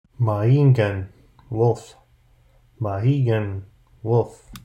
Mahìngan – Pronunciation